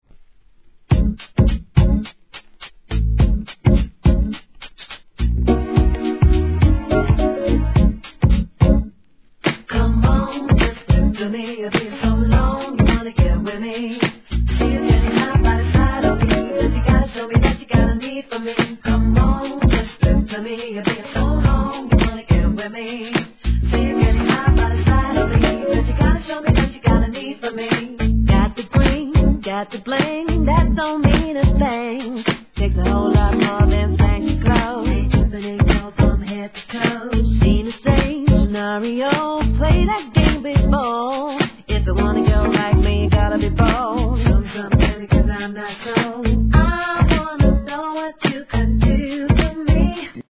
HIP HOP/R&B
特にフロア受けするであろうアッパーダンストラック